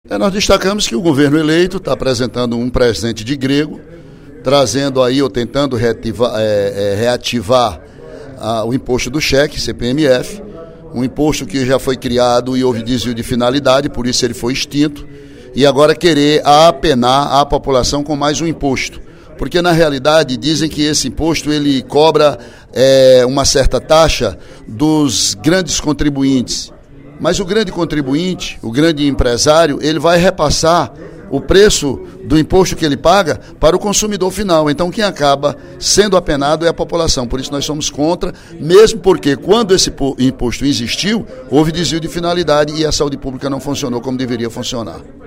Durante o primeiro expediente da sessão plenária desta quarta-feira (10/12), o deputado Ely Aguiar (PSDC) criticou a proposta da volta da cobrança da Contribuição Provisória sobre Movimentação Financeira (CPMF).